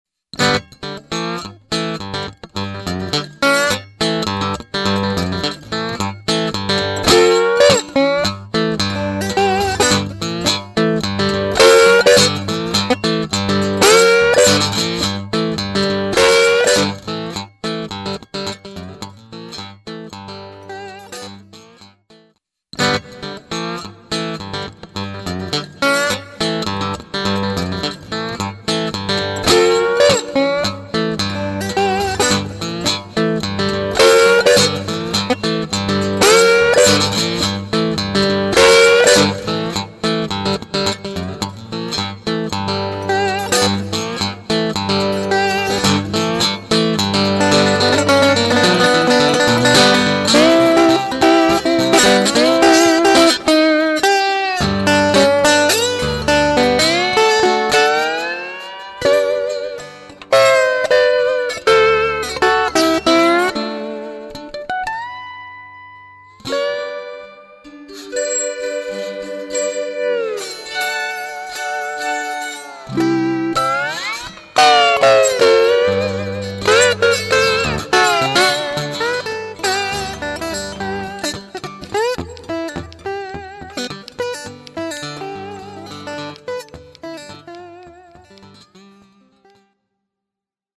The following audio clips used an indentical basic setup to show the capabilities and quality that can be obtained by using the AMP+.
The guitar was plugged straight into the Super 57 or M59 AMP+ and then routed into a Focusrite Saffire 6 USB Interface.
Some of the clips have a dry section 1st and then with added post production reverb to show what a typical studio track might sound like.
National Resonator